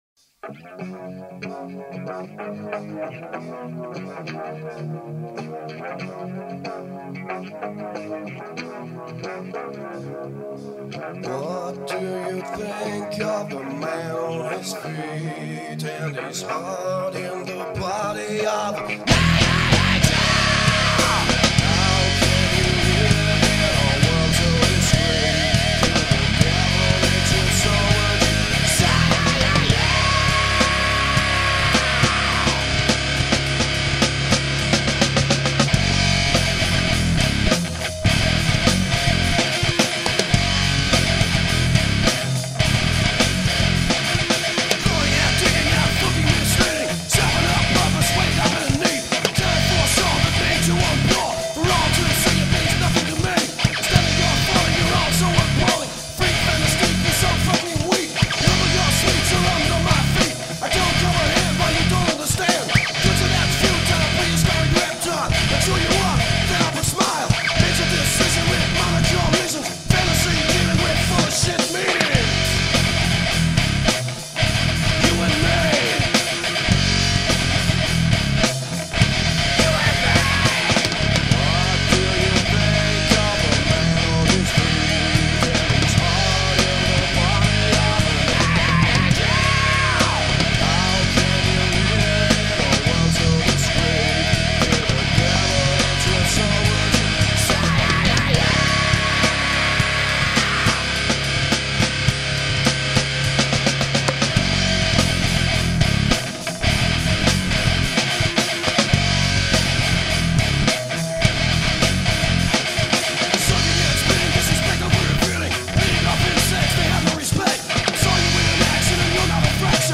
from a recent recorded rehearsal session